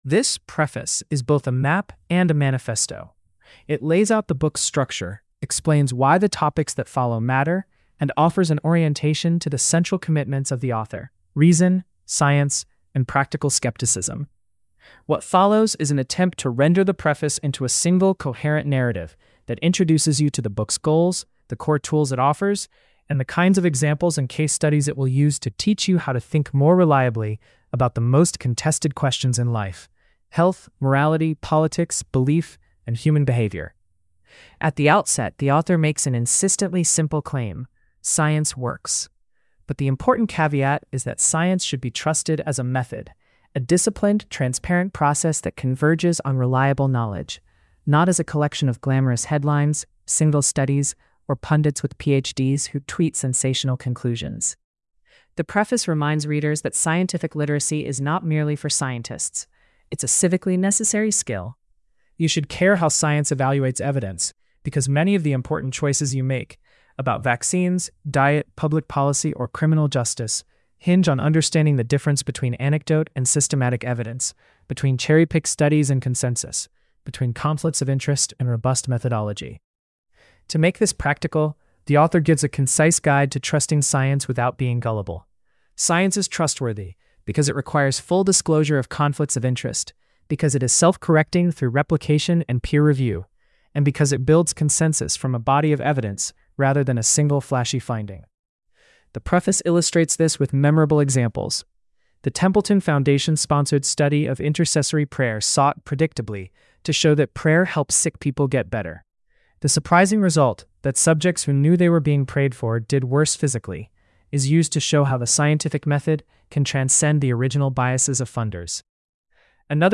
Read or listen to the AI-generated summary of